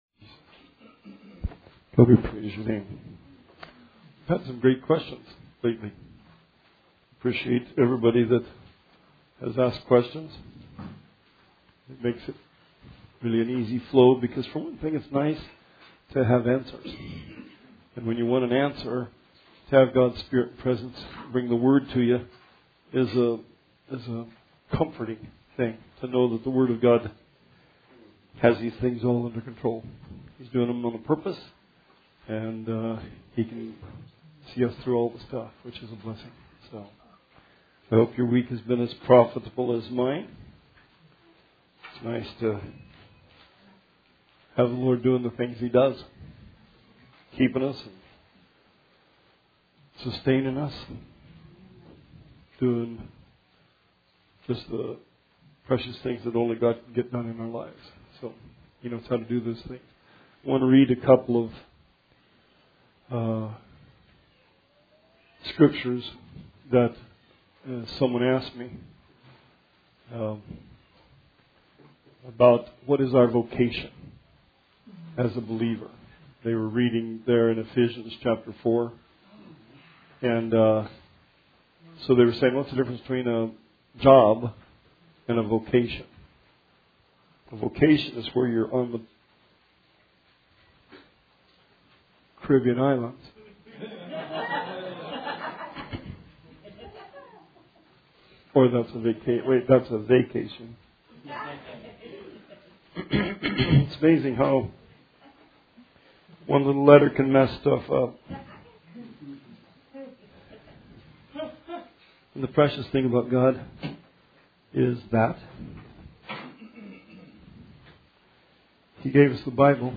Bible Study 10/23/19